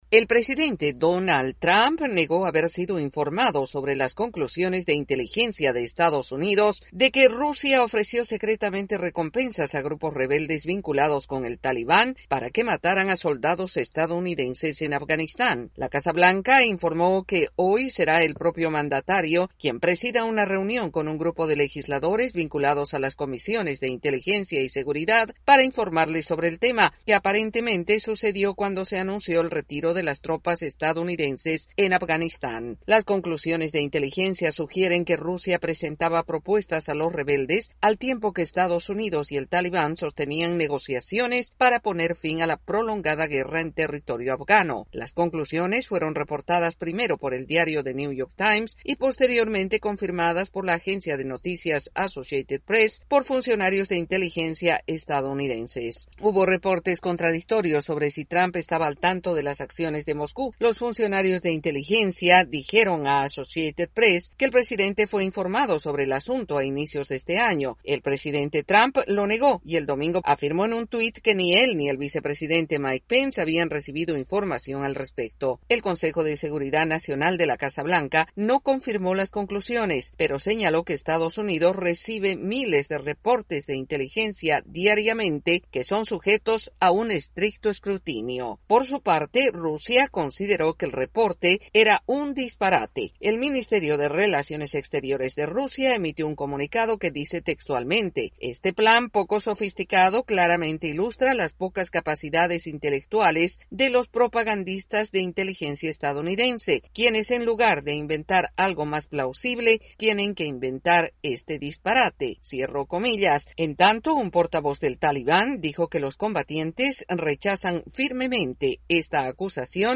El presidente Donald Trump niega conocimiento sobre presuntas recompensas de Rusia a rebeldes en Afganistán para matar soldados estadounidenses. Los detalles en el informe
desde la Voz de América en Washington DC.